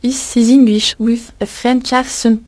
"This is english with a french accent" spoken by a french voice.
Ah, too strong accent for my project.